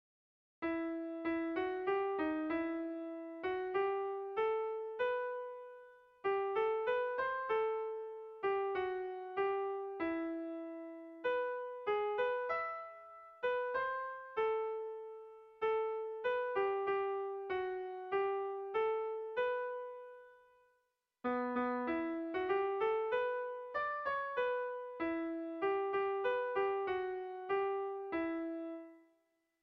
Erromantzea
ABD